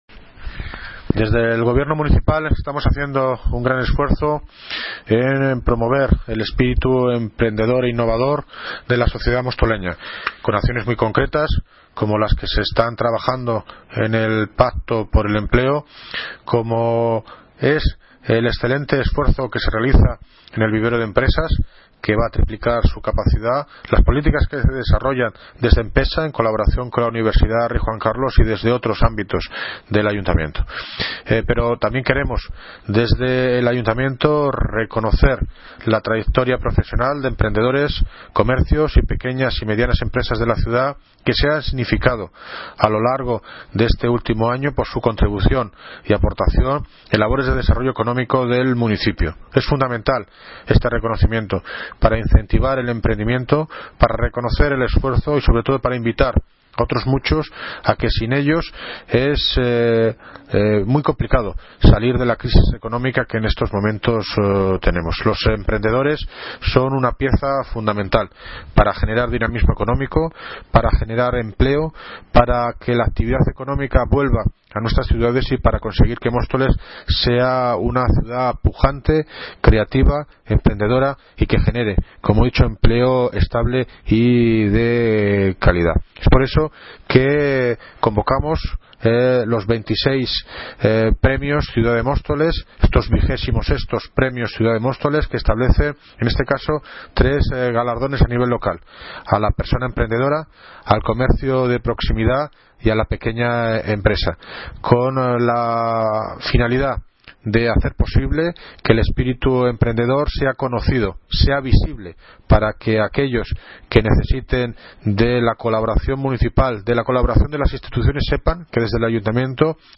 Audio - David Lucas (Alcalde de Móstoles) Sobre XXVI Premios Ciudad de Móstoles